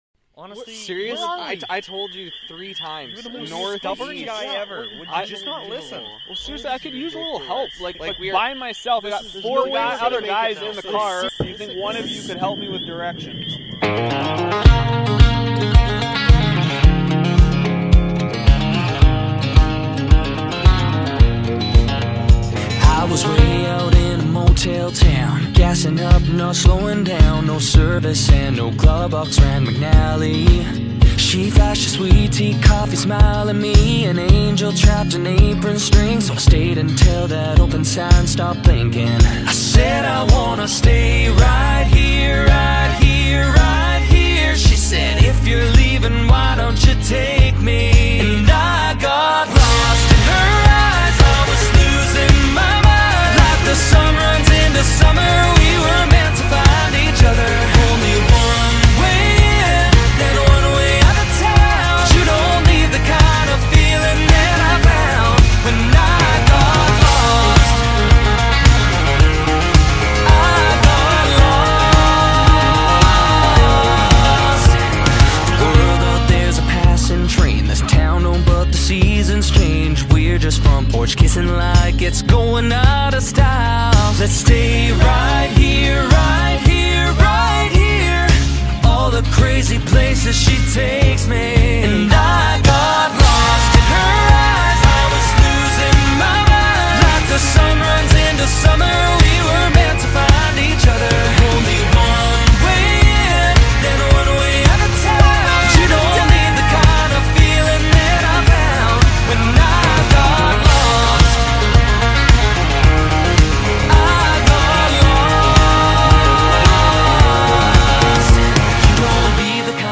country (popullore e fshatit)